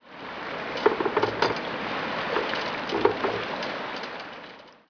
dock3.wav